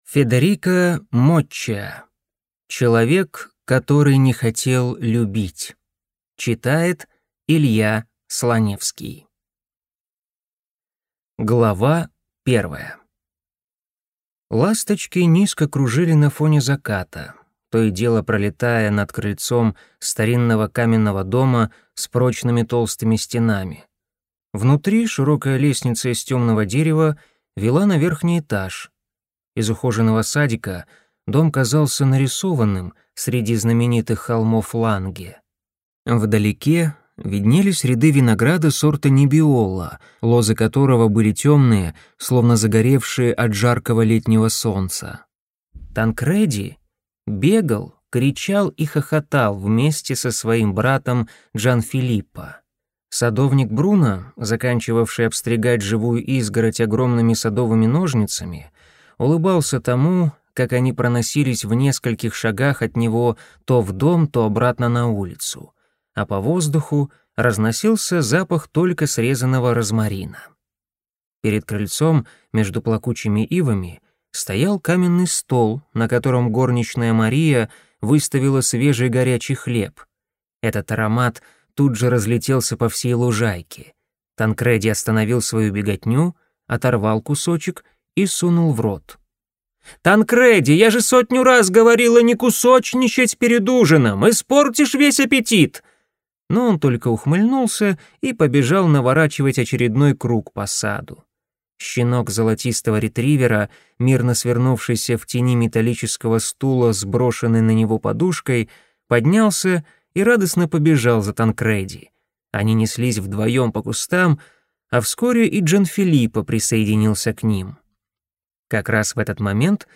Аудиокнига Человек, который не хотел любить | Библиотека аудиокниг